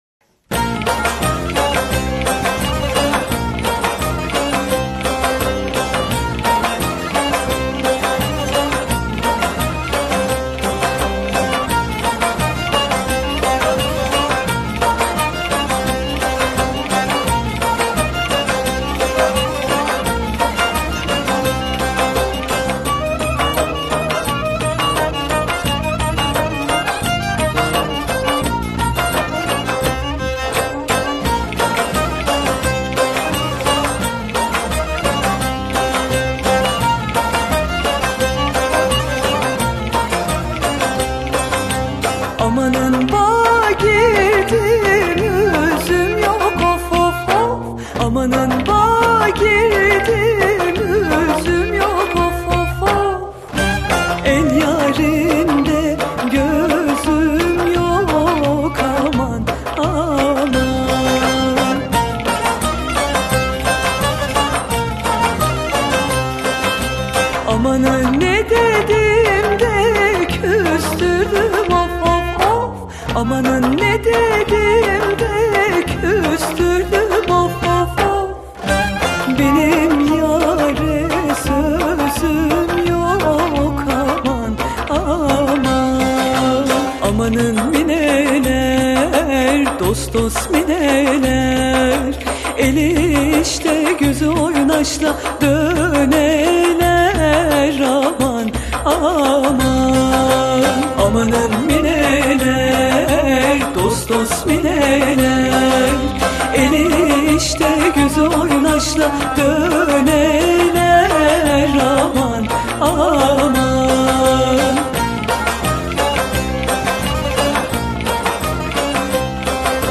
Etiketler: türkiye, türkü